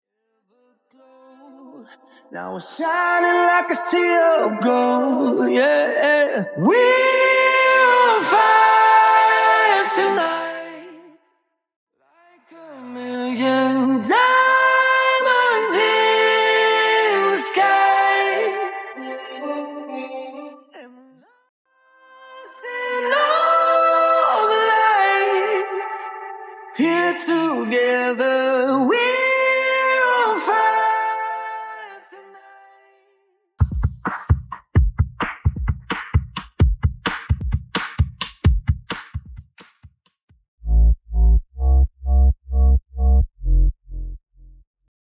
(Bassline Stem)
(Percussion & Drums Stem)